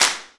Snare drum alternatives Free sound effects and audio clips
• Clean Hand Clap Sound C# Key 36.wav
Royality free clap one shot - kick tuned to the C# note. Loudest frequency: 5171Hz
clean-hand-clap-sound-c-sharp-key-36-KbJ.wav